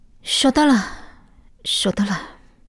c02_6偷听对话_李氏_3.ogg